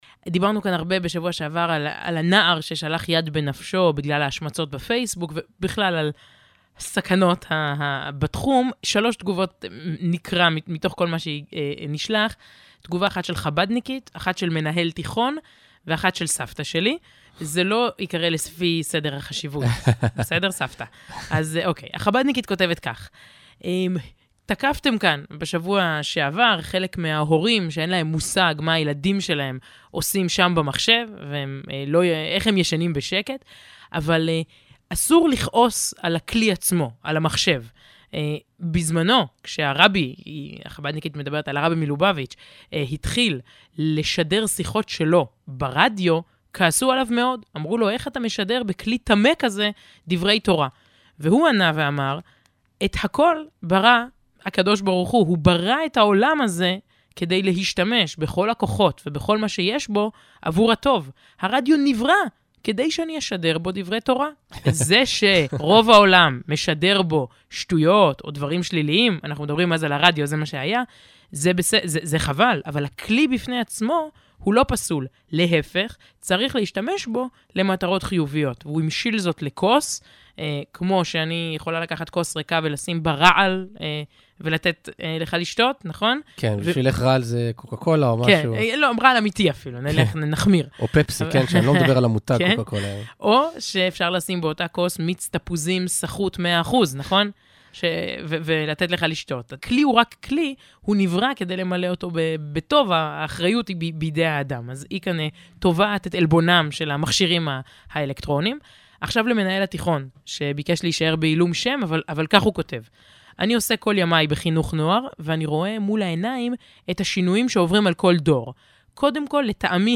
בתכניתם השבועית, המשודרת מדי יום שישי ב-12 ב"גלי-צה"ל", מרבים העיתונאים ידידיה וסיון מאיר להתייחס לנושאים יהודיים וחסידותיים.
השבוע, הקריאו השניים מכתב שקיבלו מחסידת חב"ד. לאחר שבתוכנית קודמת דיברו על סכנות התקשורת ובעיקר המחשב והאינטרנט, כתבו להם מספר מאזינים תגובות, וביניהם חסידת חב"ד.